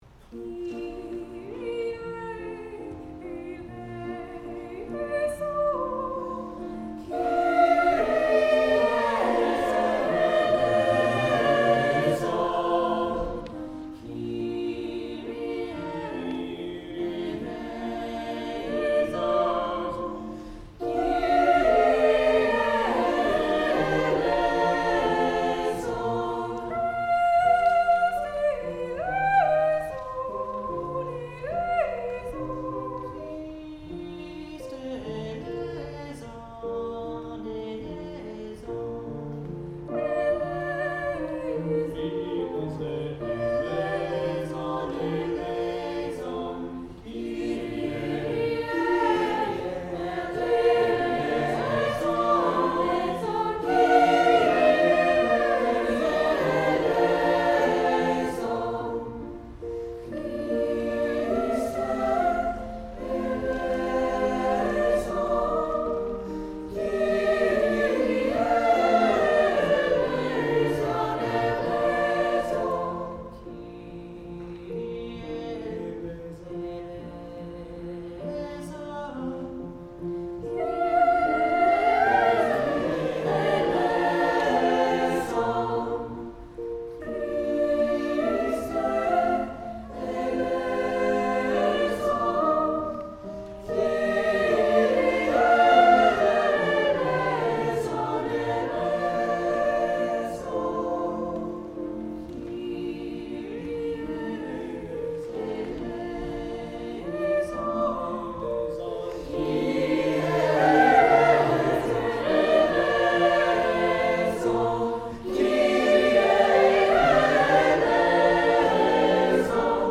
Chamber, Choral & Orchestral Music